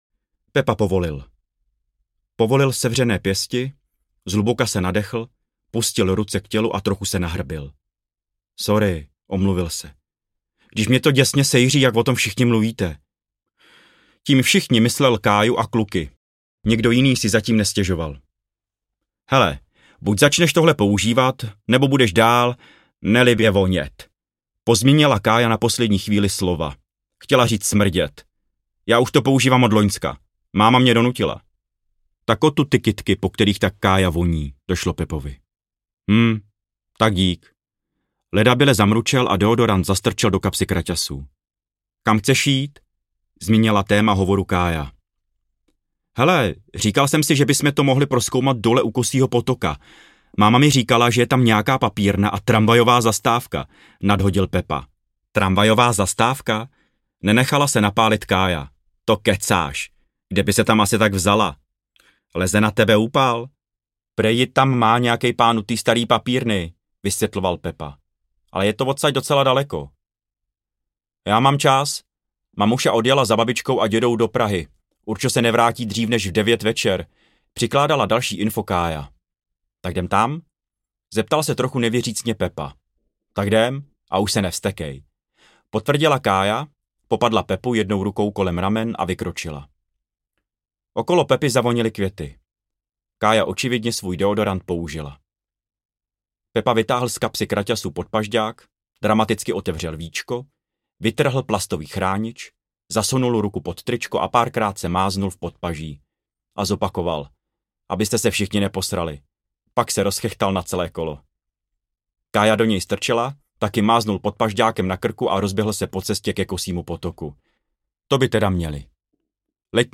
Polednice Plešatá palice audiokniha
Ukázka z knihy